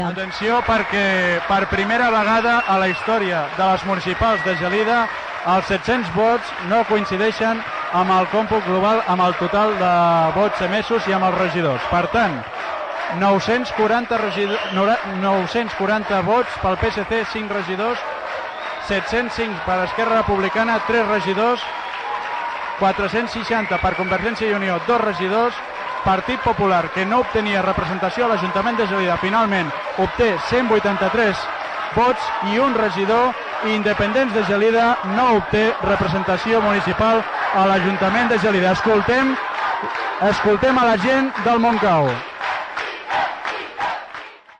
6b343ff416ad0530d7511b427c144d38920a09d5.mp3 Títol Ràdio Gelida Emissora Ràdio Gelida Titularitat Pública municipal Descripció Programa especial de les eleccions municipals 1990. Resultat de les eleccions a Gelida Gènere radiofònic Informatiu